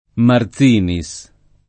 [ mar Z& ni S ]